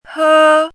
chinese-voice - 汉字语音库